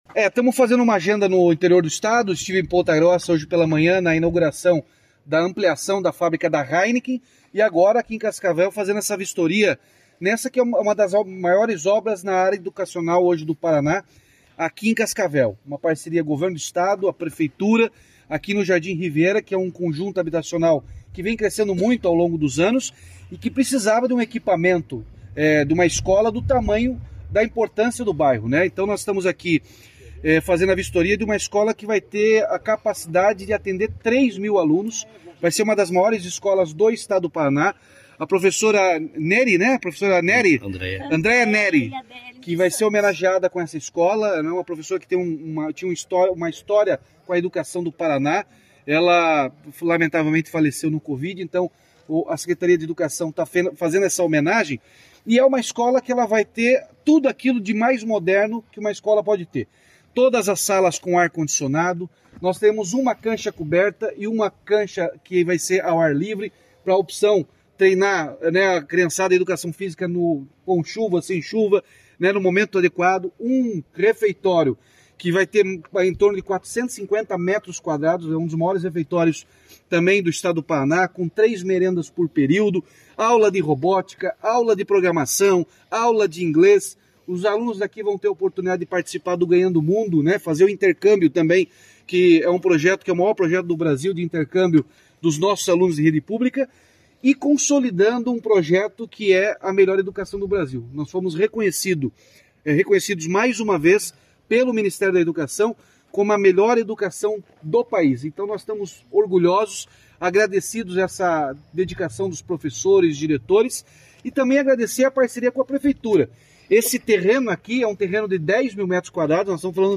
Sonora do governador Ratinho Junior sobre obra da nova escola estadual de Cascavel no Jardim Riviera